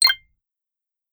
notification-1.DSse8d1Q.mp3